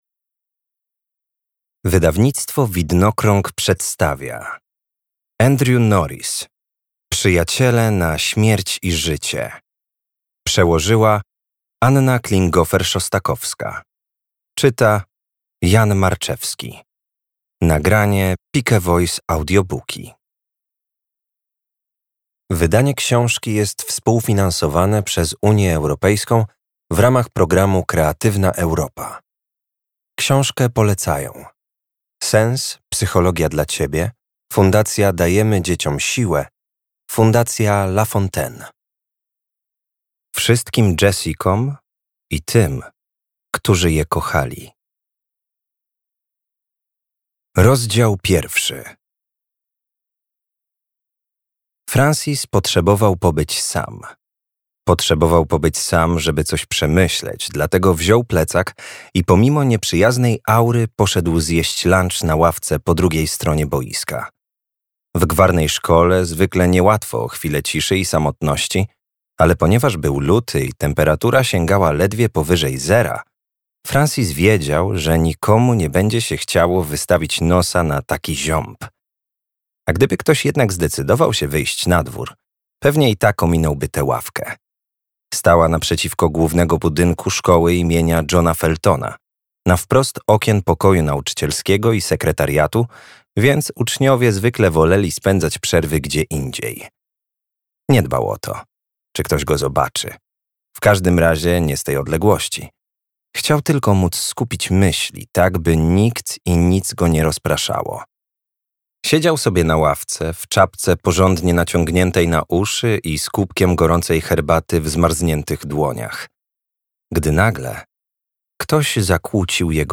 Przyjaciele na śmierć i życie - Andrew Norriss - audiobook